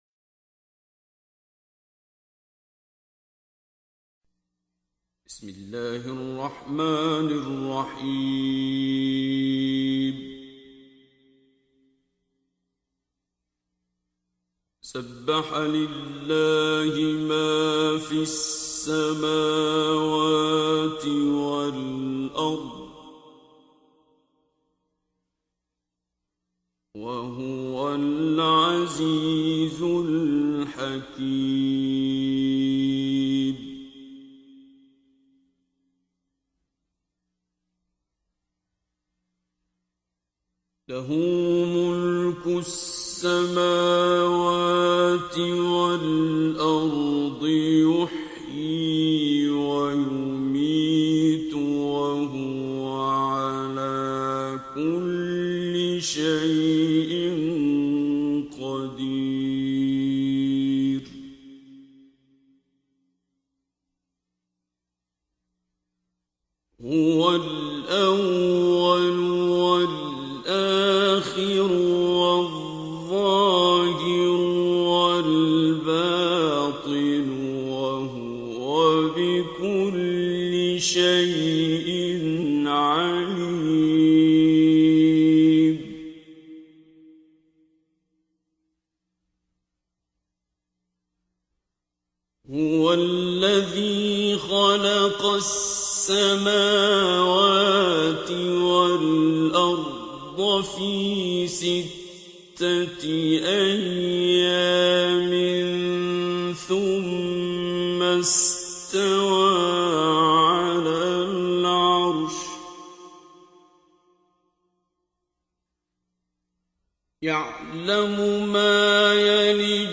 دانلود تلاوت زیبای سوره حدید آیات 1 الی 29 با صدای دلنشین شیخ عبدالباسط عبدالصمد
در این بخش از ضیاءالصالحین، تلاوت زیبای آیات 1 الی 29 سوره مبارکه حدید را با صدای دلنشین استاد شیخ عبدالباسط عبدالصمد به مدت 28 دقیقه با علاقه مندان به اشتراک می گذاریم.